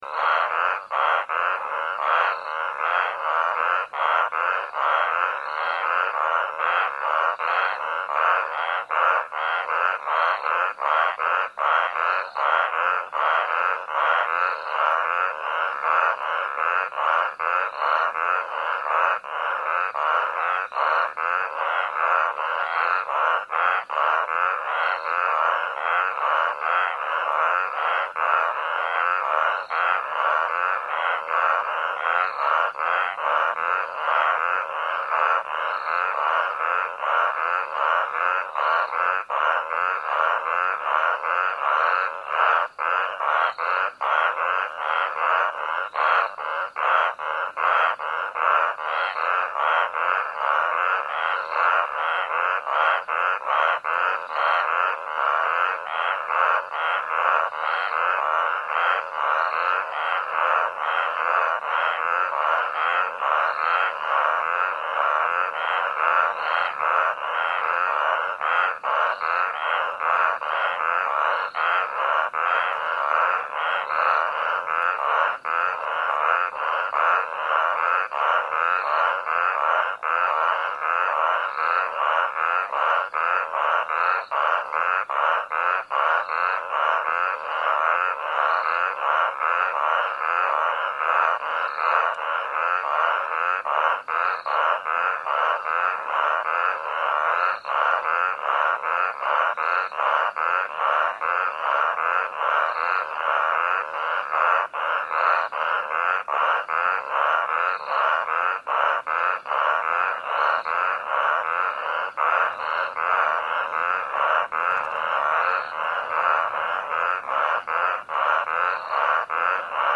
Frog Night Assembly